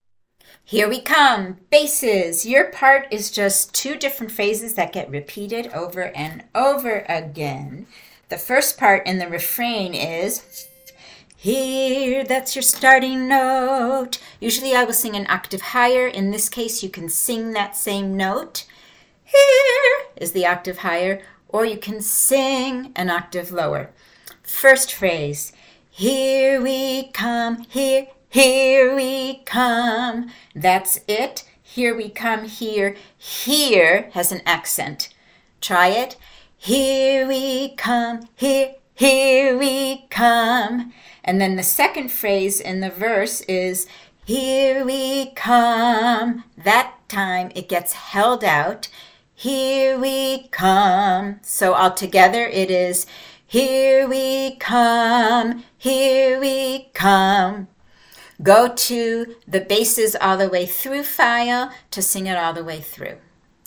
Here We Come bass-alto.mp3